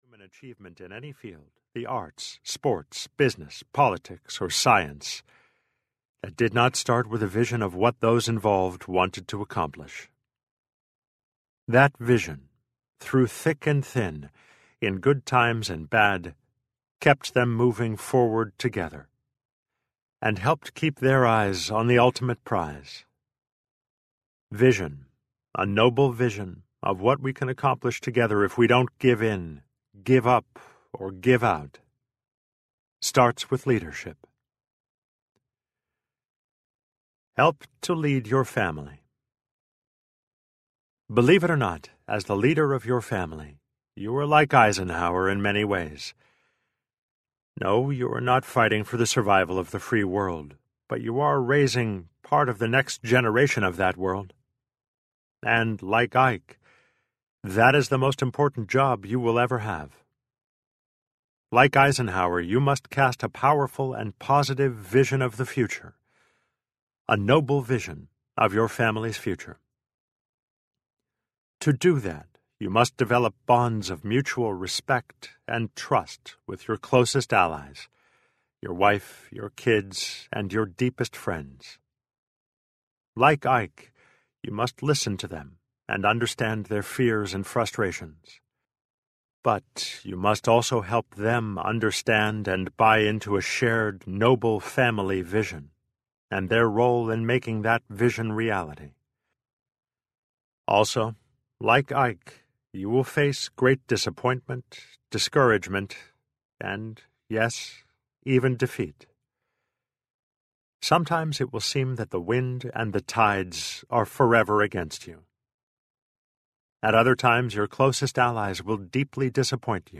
Be A Better Dad Today Audiobook
Narrator